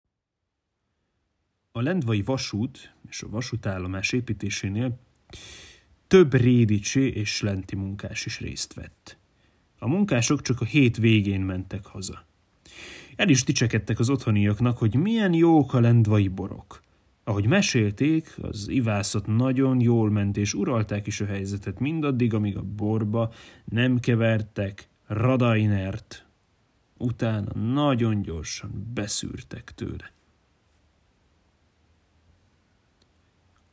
felolvassa